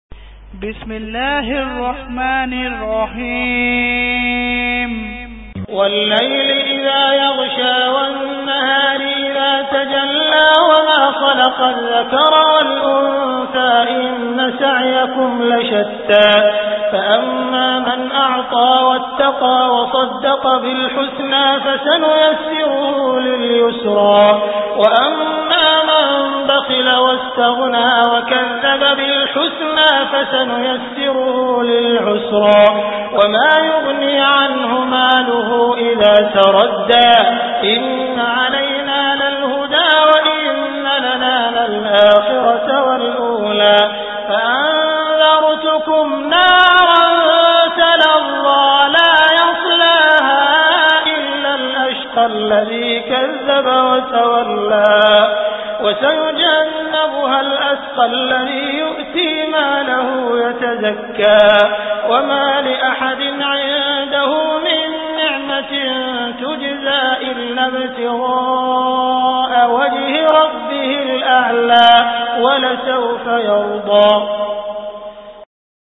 Surah Al Lail Beautiful Recitation MP3 Download By Abdul Rahman Al Sudais in best audio quality.